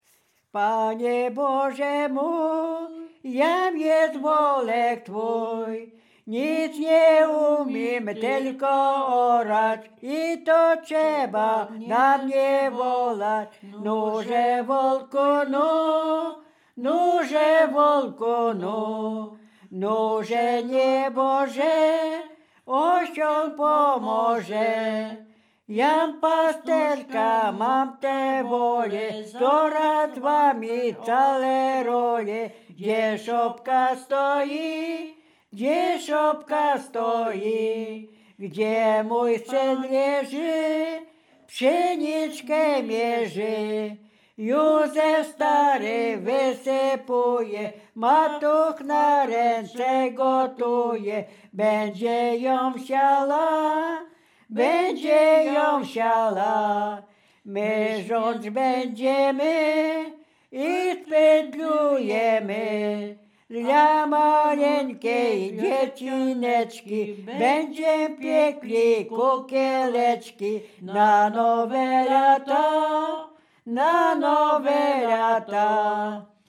województwo dolnośląskie, powiat lwówecki, gmina Lwówek Śląski, wieś Zbylutów
Kolęda
kolędy życzące zima kolędy kolędowanie gody nowe lato